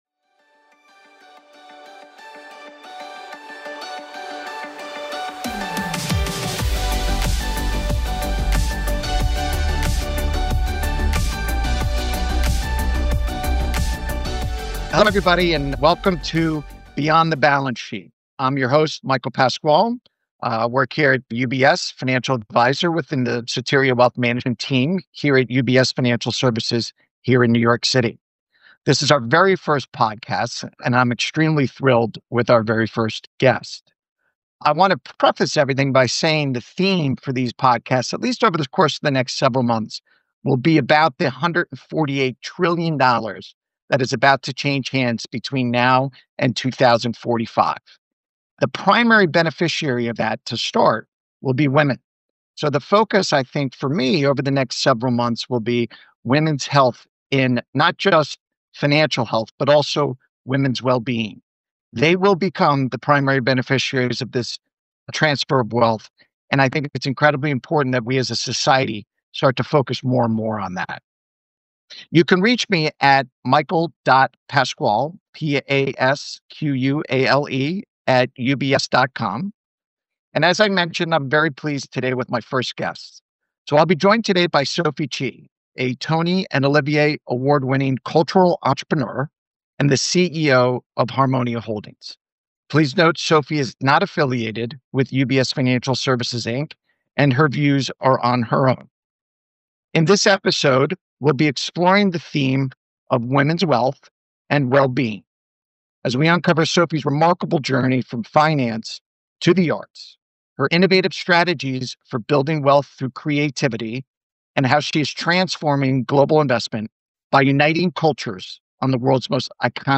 Through candid conversations with creators and leaders, the podcast uncovers how financial decisions, cultural influence, and personal legacy intertwine.